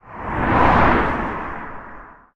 car3.ogg